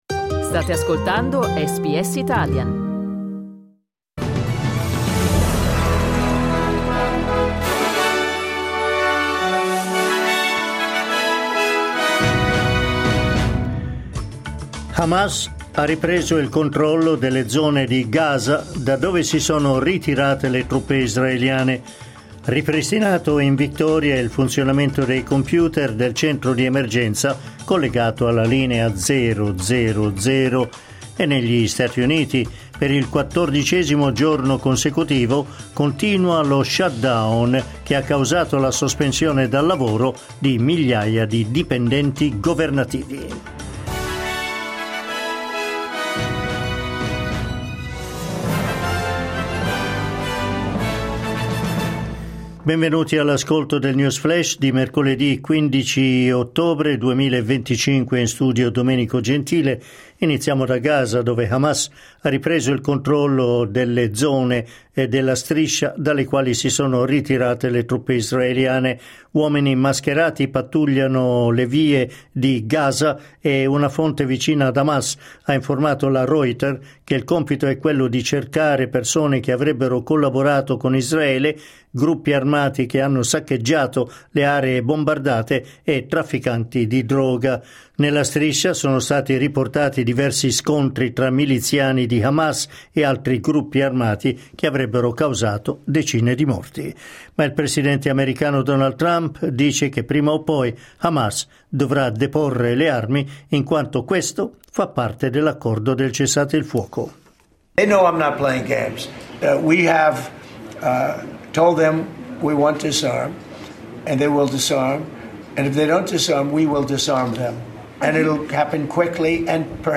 News flash mercoledì 15 ottobre 2025